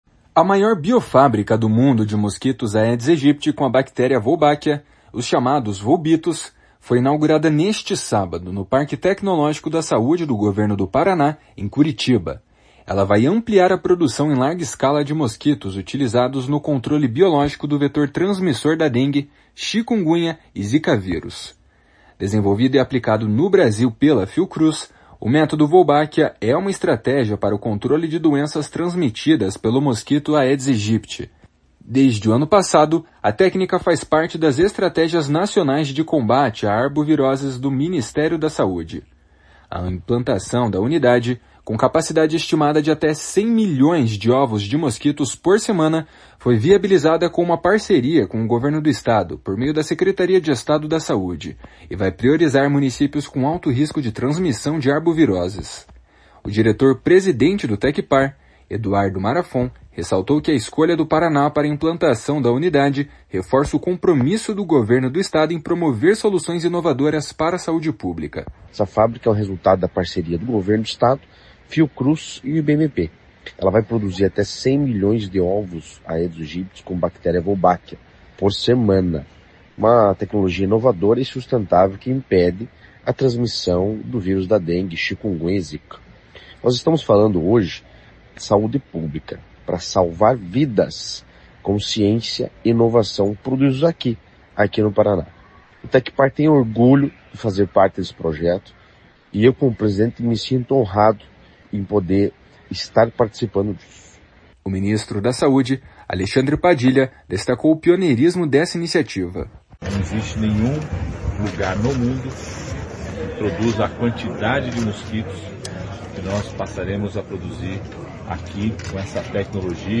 O ministro da Saúde, Alexandre Padilha, destacou o pioneirismo dessa iniciativa. // SONORA ALEXANDRE PADILHA //